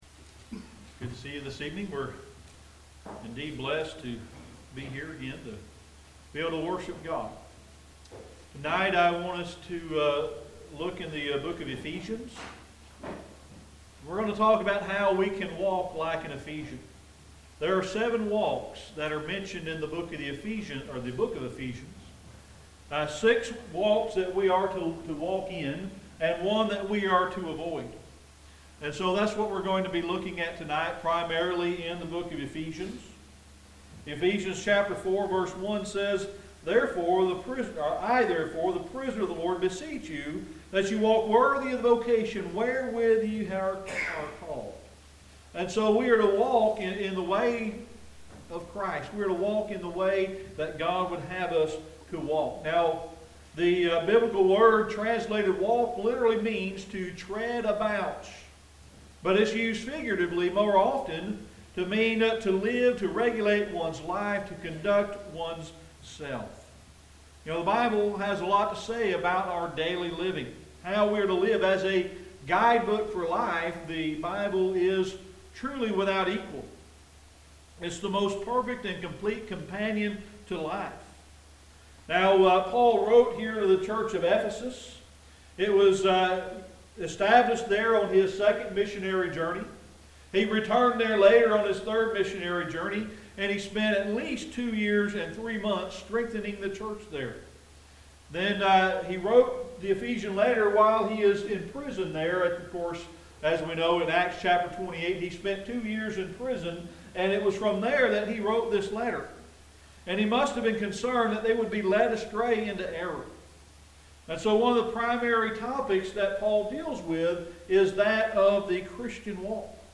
Audio Sermon: Seven Walks of the Ephesians | Oak Grove Church of Christ
Audio Sermon: Seven Walks of the Ephesians